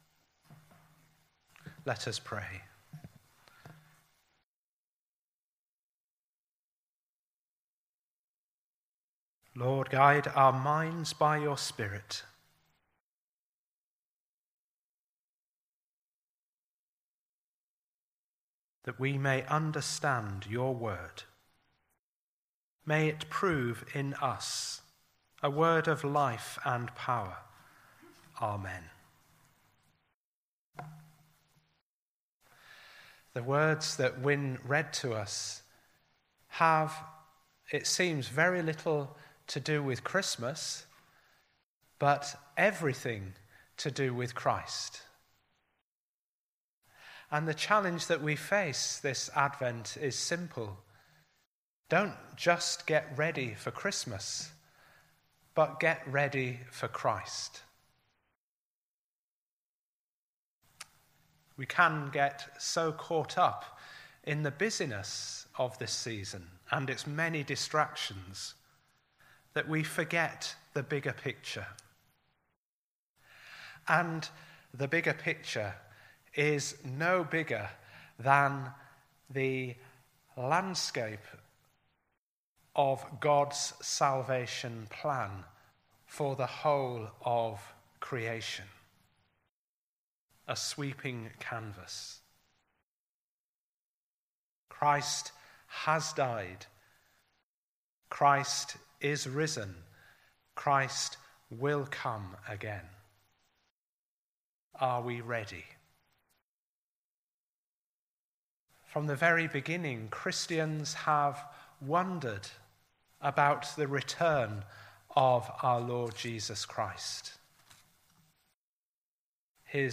A service of evening worship in a traditional style, including Holy Communion.